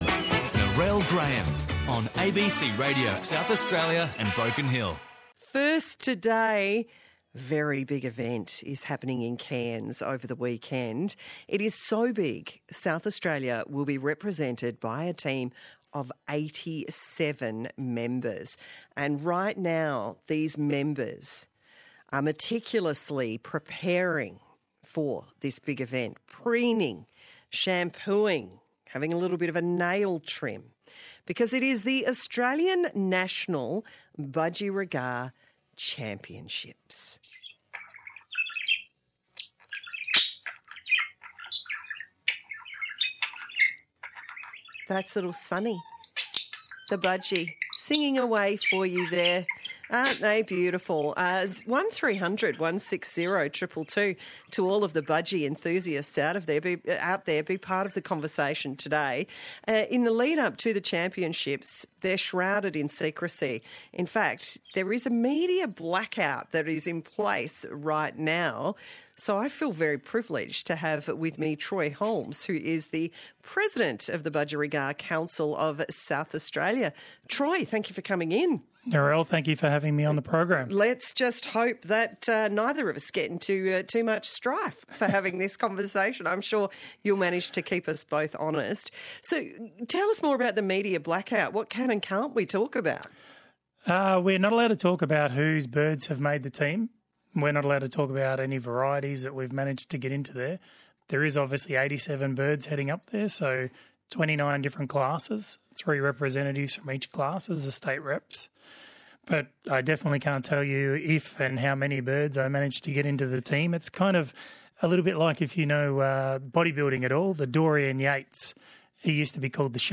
Listen to the ABC Regional Drive interview May 19 2025 with insights on the ANBC National Titles being held in May 2025.